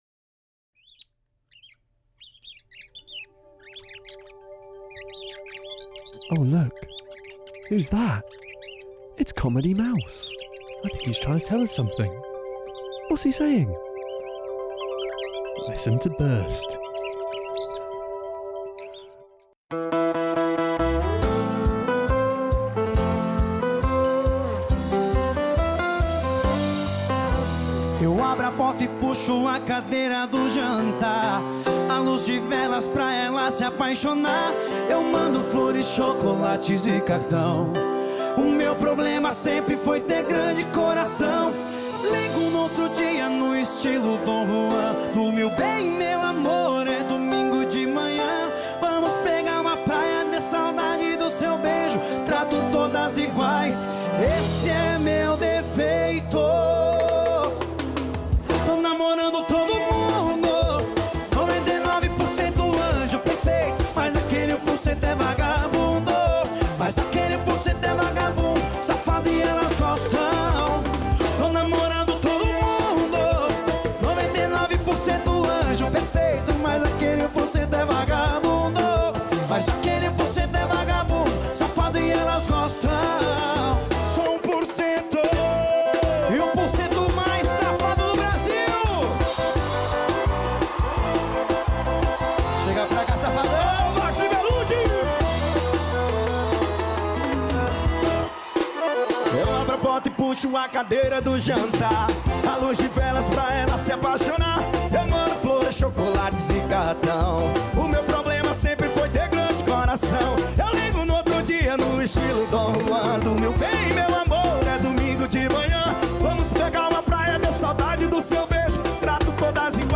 Be prepared to dance over the exciting rhythms of sertanejo, funk, axé and more.